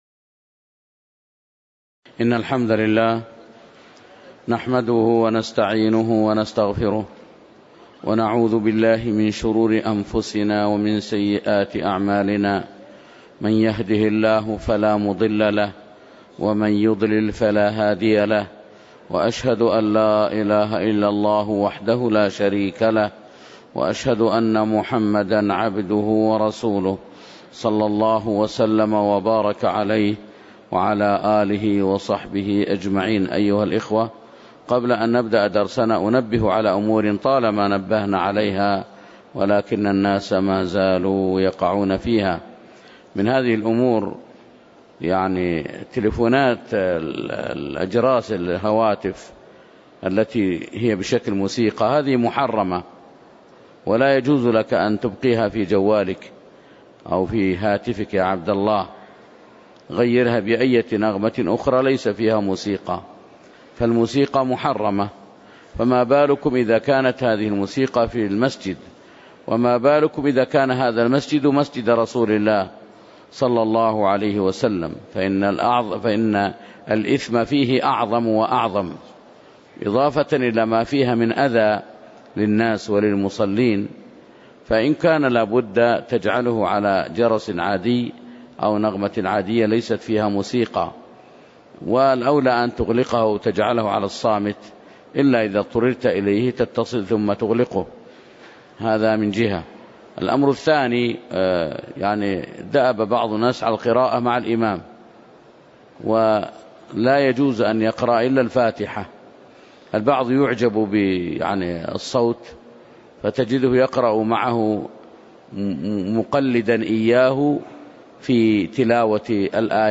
تاريخ النشر ٦ ذو القعدة ١٤٣٨ هـ المكان: المسجد النبوي الشيخ